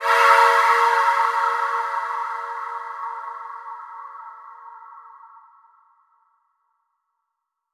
Aaahs Kanye A#dim [c# e a#].wav